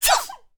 minecraft / sounds / mob / panda / sneeze2.ogg
sneeze2.ogg